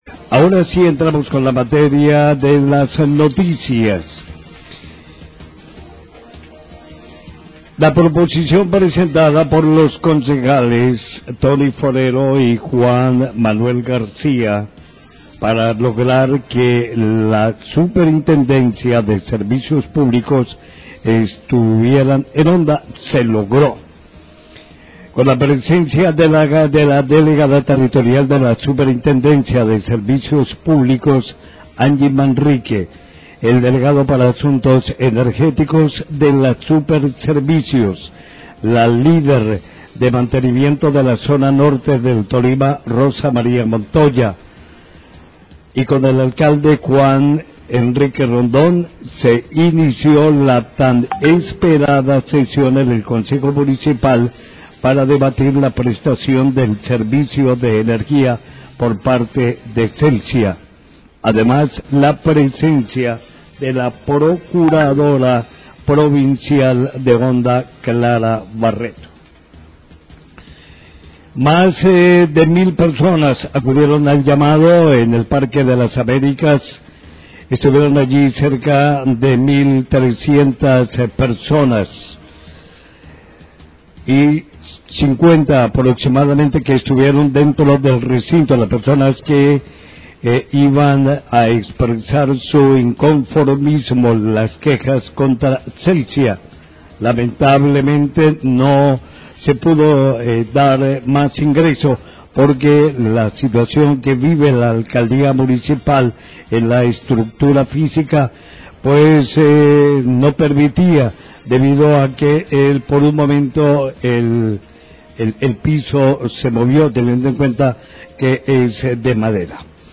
Radio
Informativo de Olímpica Honda